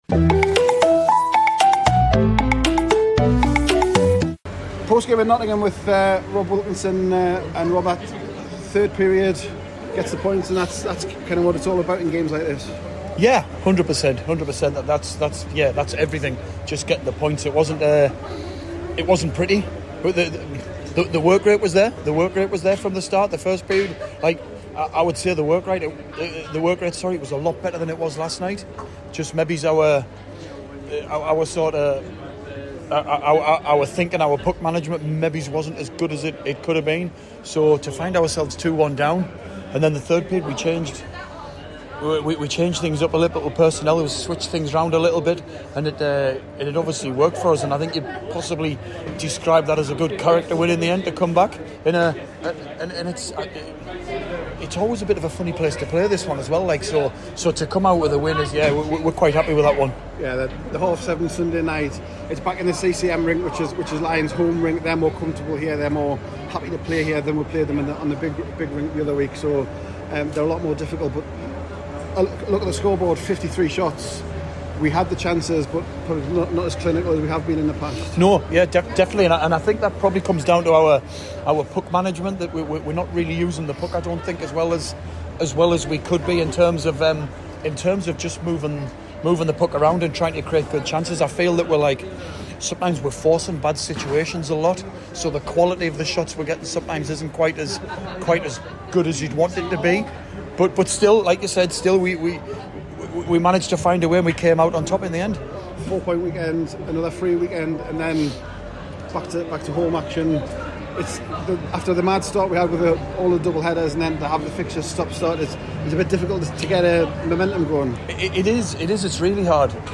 an emotional chat